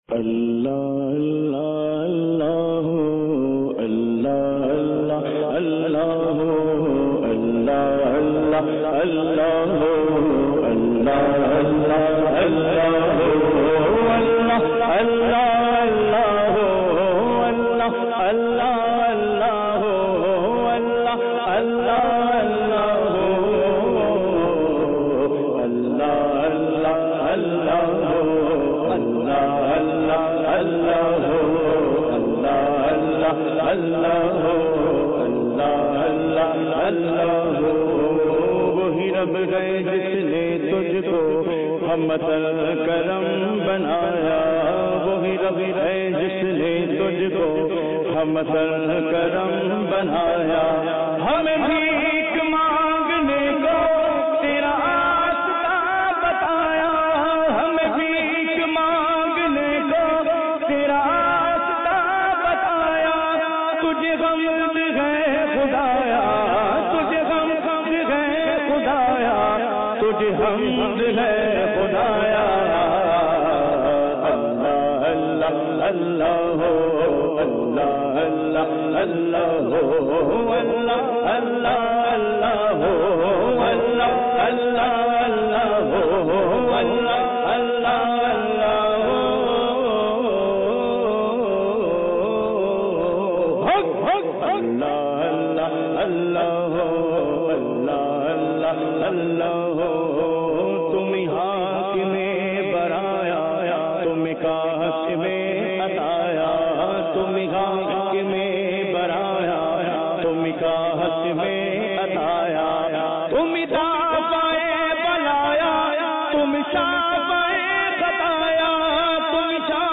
اجتماعِ ذکرونعت میں پڑھا جانے والا
نئی اور منفرد طرز اور خوبصورت آواز میں